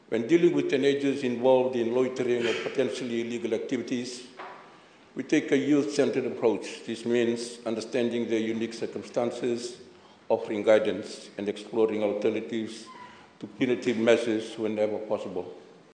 Home Affairs Minister, Pio Tikoduadua.